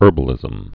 (ûrbə-lĭzəm, hûr-)